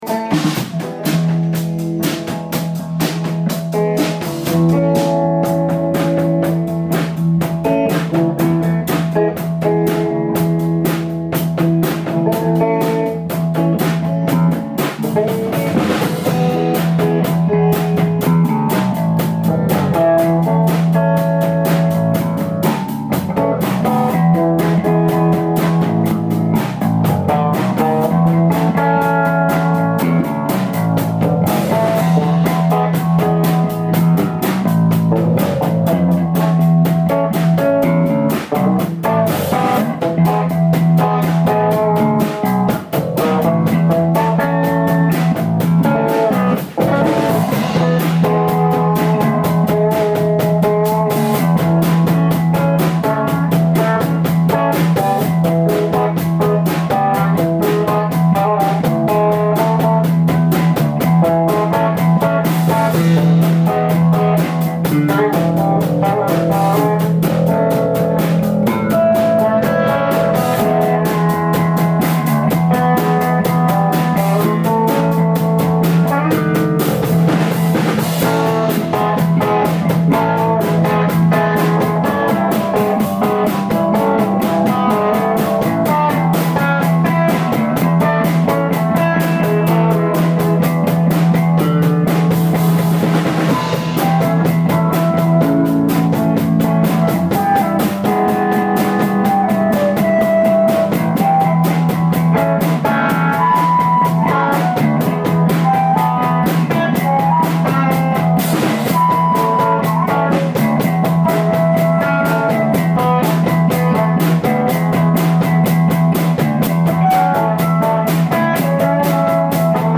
OHR, JULY 17th (QUARTET) , 2016
ALL MUSIC IS IMPROVISED ON SITE